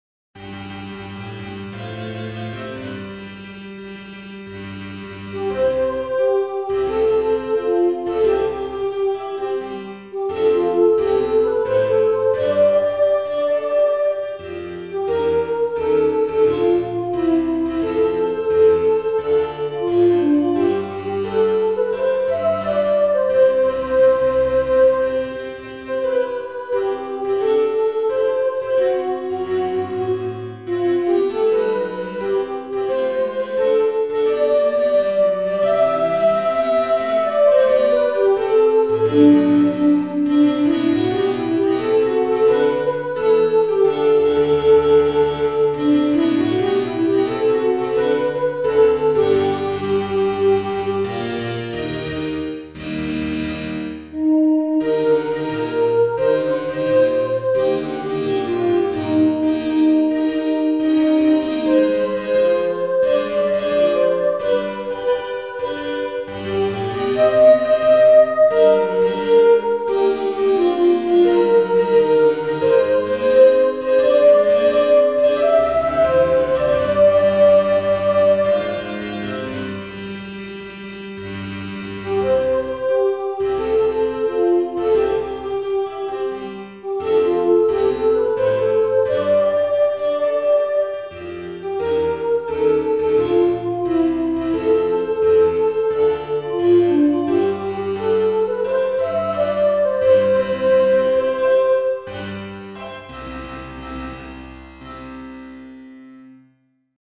Base musicale dell’Inno dell’Istituto
base_inno.mp3